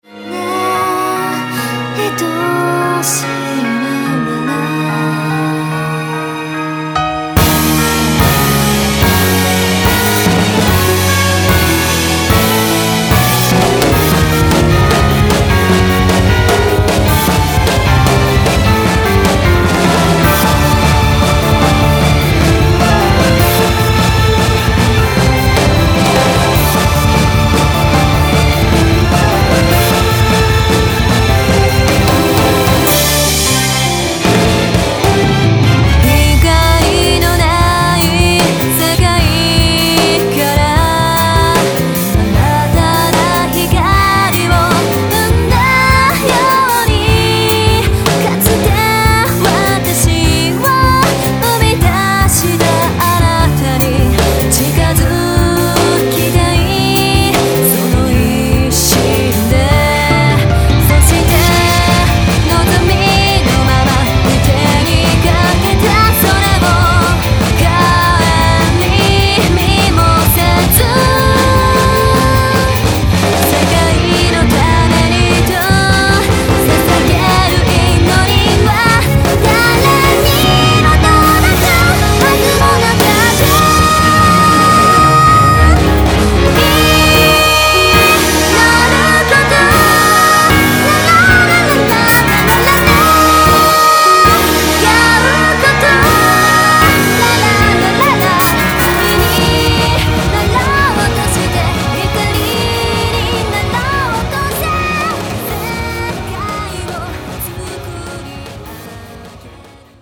東方マルチジャンルアレンジアルバムです。
Vocal
Guitar